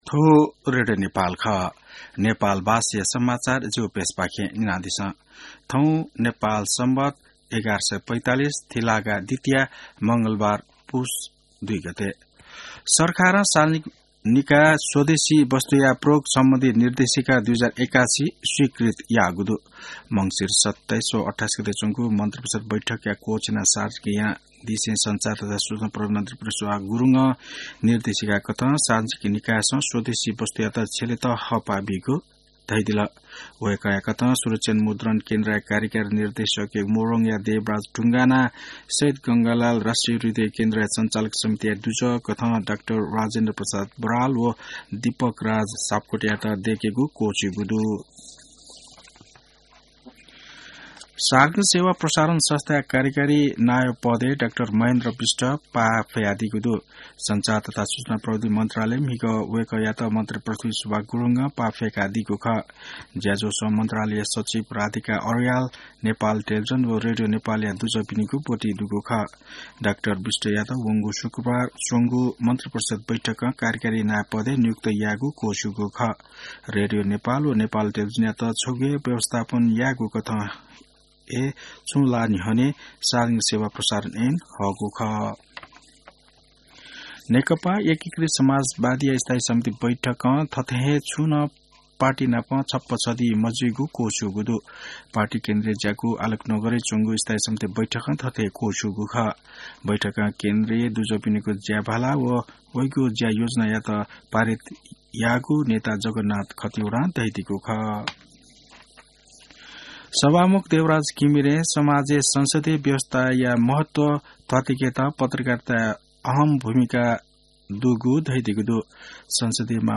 नेपाल भाषामा समाचार : ३ पुष , २०८१